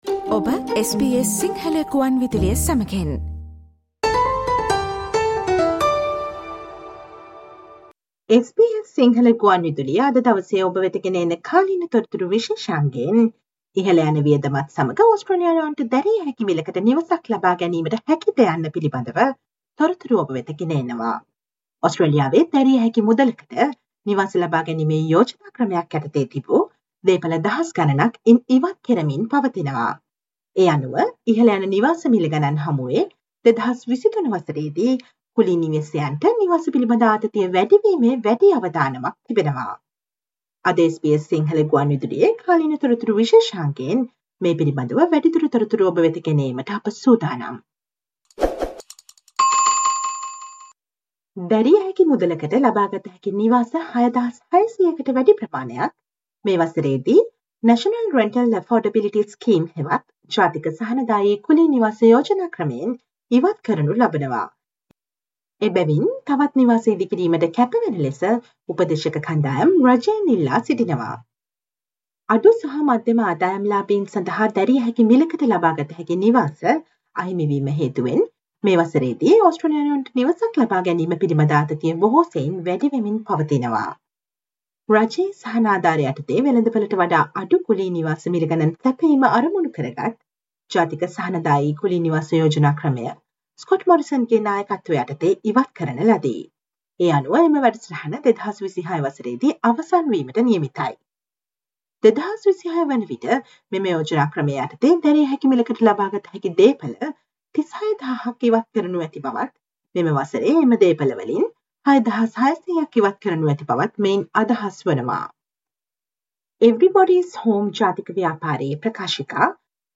Listen to the SBS Sinhala radio current affair feature on increased risk of housinf stress in 2023 as Australians face significant rental rise while thousands of properties are being wiped from an affordable housing scheme.